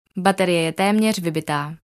Hovorí po slovensky
Vysávač informuje o svojom chode správami v slovenskom jazyku.